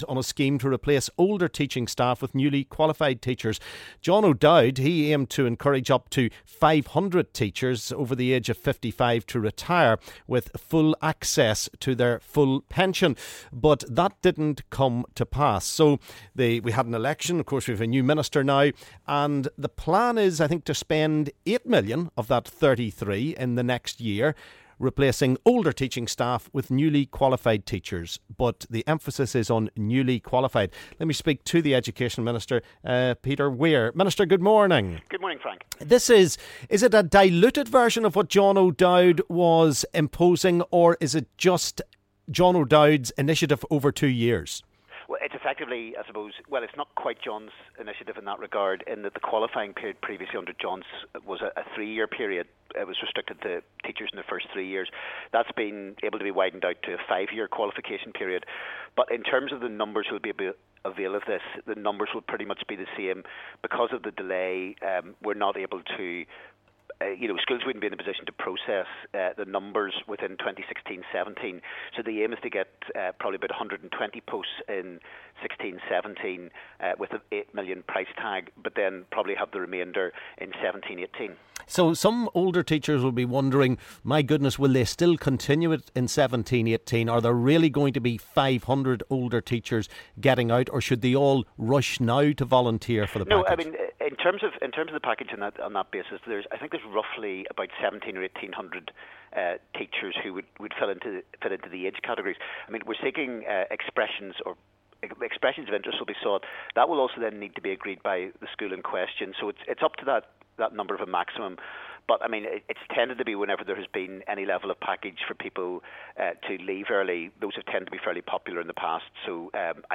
LISTEN: Education Minister on the scheme to replace older teachers with newly qualified teachers.